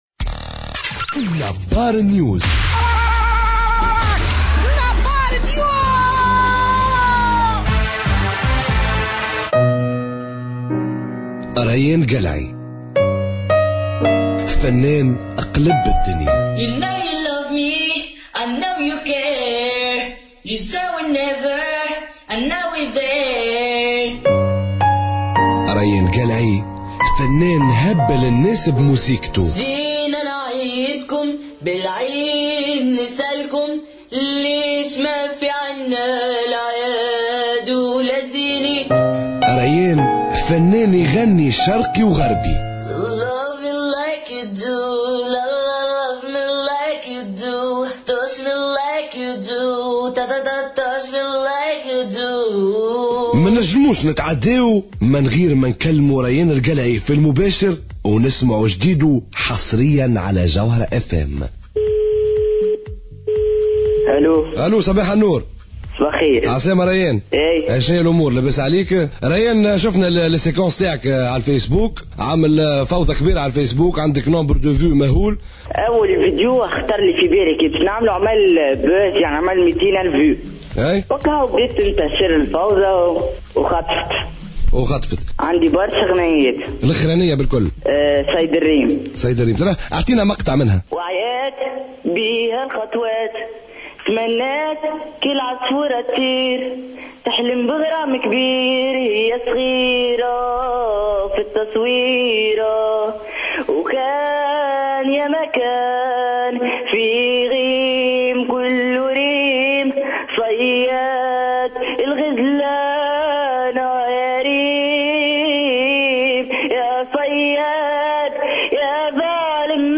chante en direct